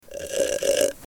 Slurping Soda
Slurping_soda.mp3